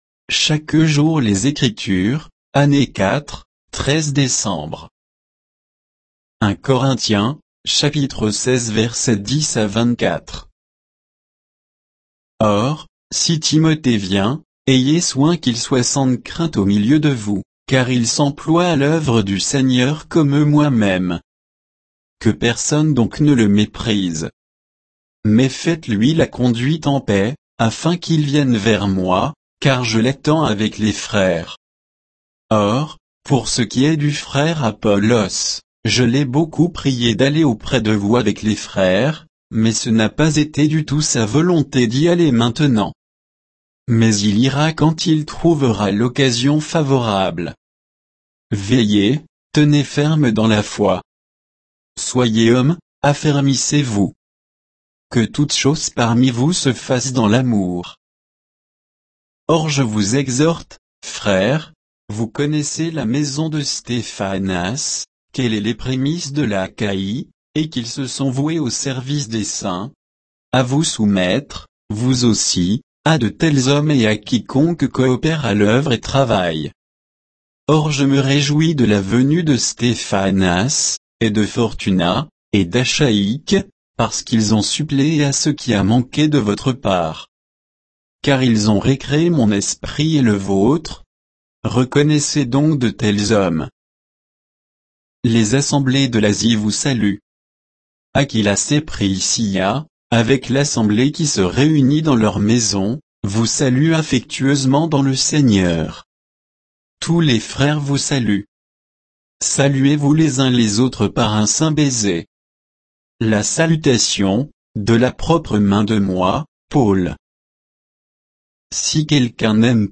Méditation quoditienne de Chaque jour les Écritures sur 1 Corinthiens 16